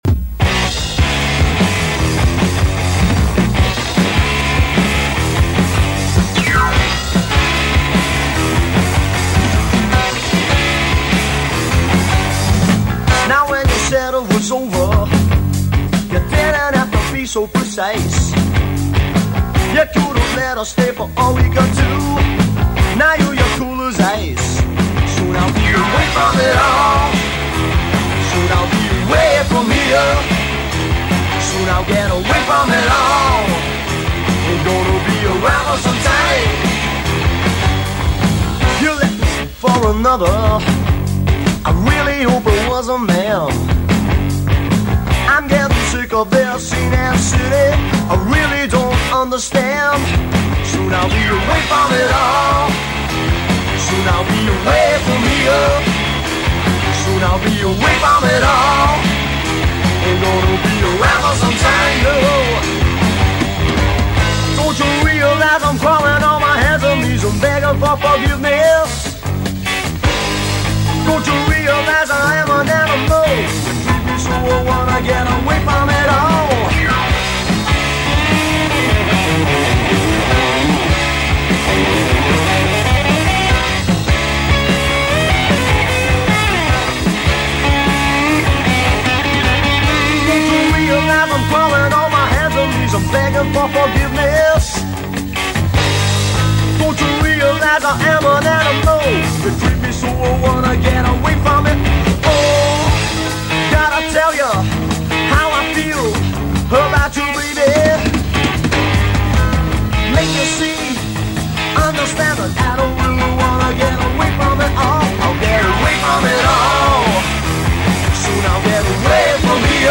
short-lived Scottish PunkPost/Punk/Power Pop band
Power Pop/Punk/new Wave